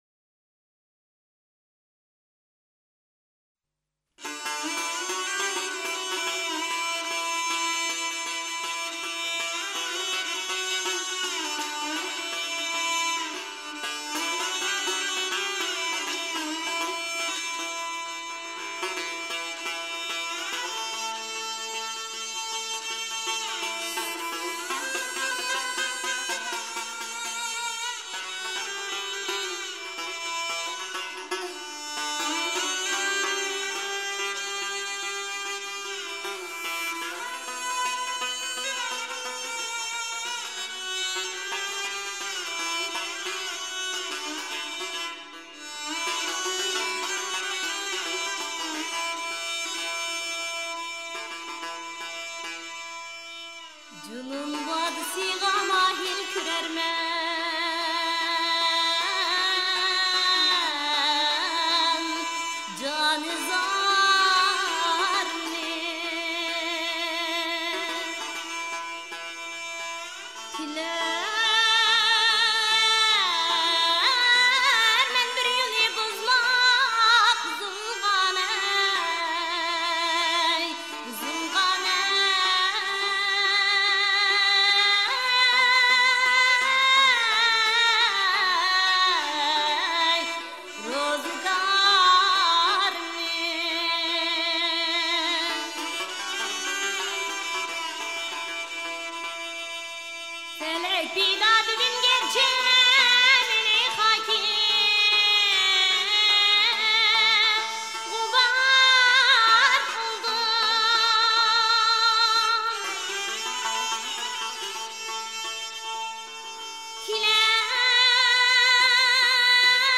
If there is any vestige of an improvised tradition in the Twelve Muqam, then it would be these muqäddimä sections, which are structured like an exploration of the mode.
The muqaddime
The Uyghur muqaddime are most wonderful accompanied by the resonant satar long-necked bowed lute. I am particularly entranced by the intense muqaddime of Özhal muqam—perhaps because of its tonal variety, with new scales, featuring a flat 7th and sharp 4th, introduced gradually. Here’s a 1997 recording: